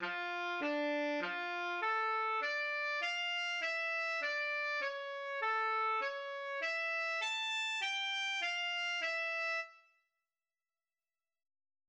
\relative c' { \set Staff.midiInstrument = "tenor sax" f d f a d f e d cis a cis e a g f e }